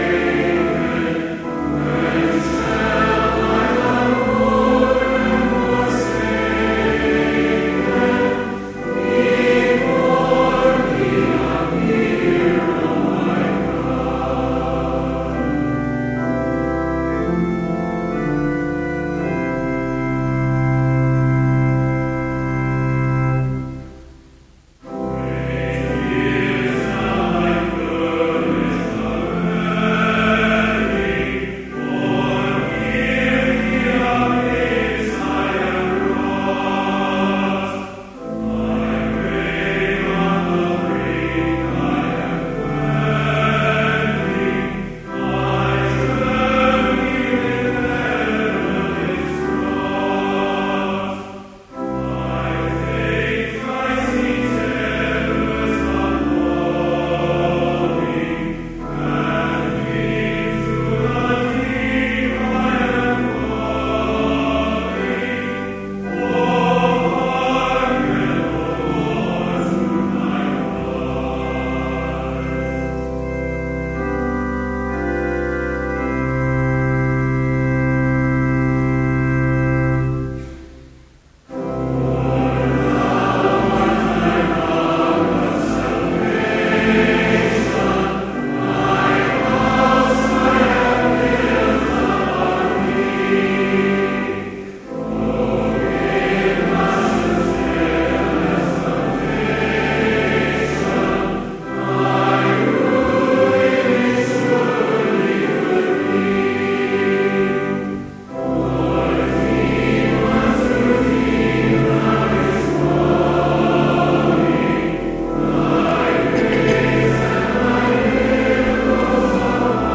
Complete service audio for Chapel - September 6, 2013